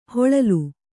♪ hoḷalu